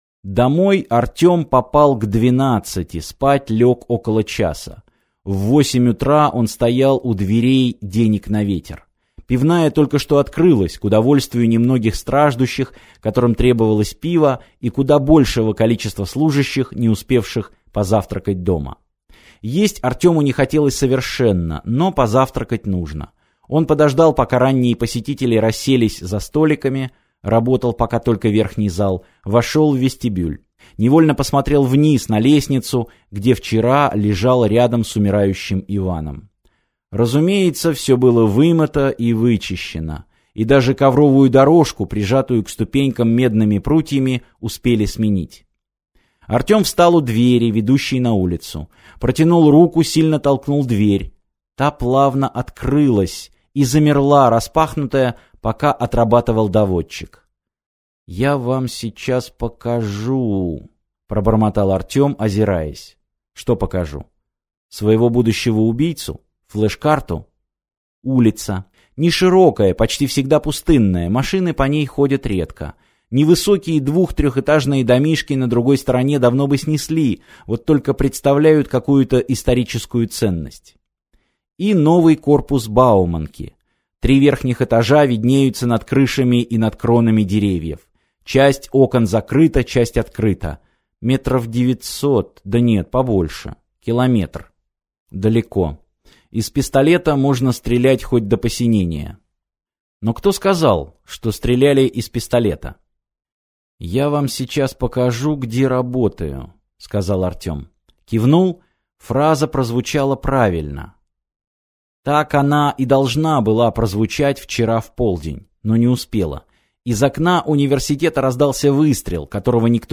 Аудиокнига Кредо | Библиотека аудиокниг